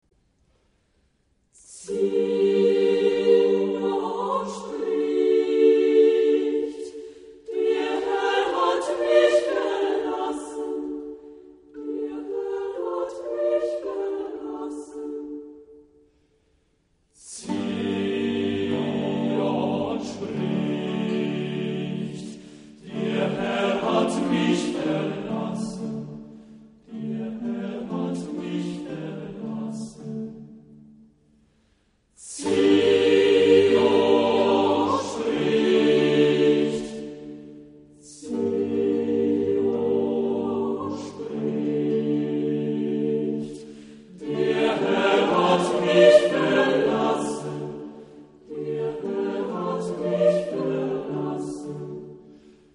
Genre-Stil-Form: geistlich ; Barock ; Motette
Chorgattung: SSATB  (5 gemischter Chor Stimmen )
Instrumentation: Continuo  (1 Instrumentalstimme(n))
Instrumente: Cembalo (1) oder Orgel (1)
Tonart(en): g-moll